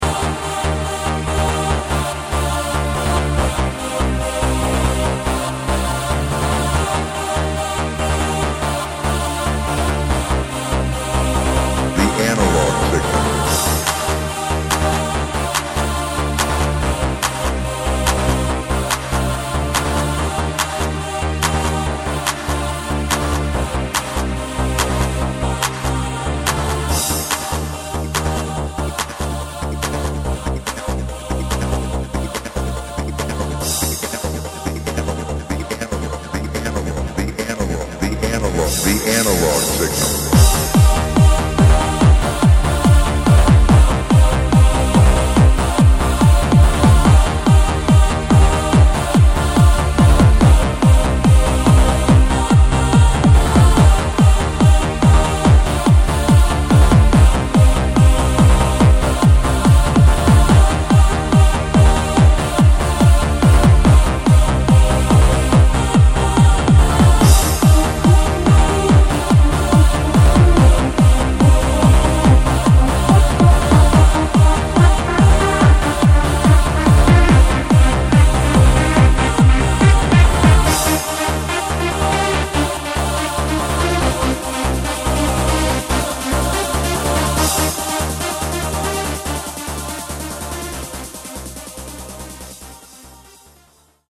Hard-Trance, Trance